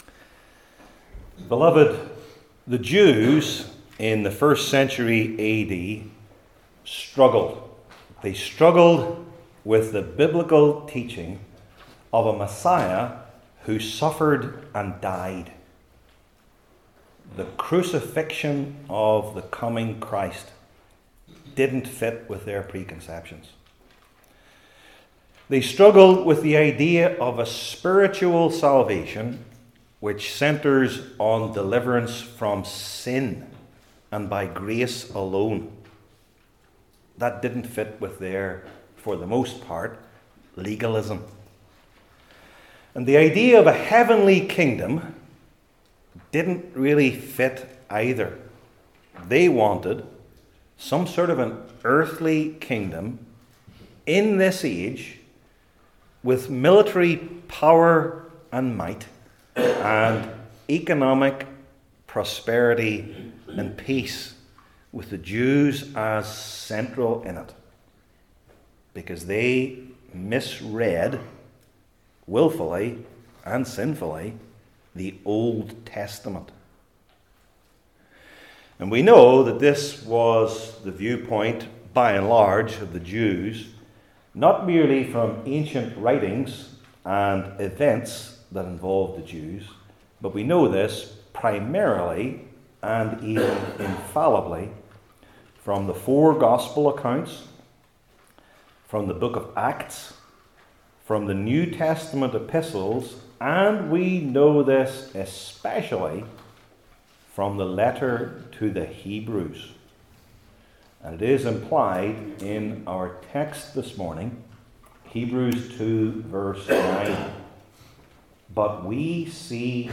Hebrews 2:9 Service Type: New Testament Sermon Series I. What We See II.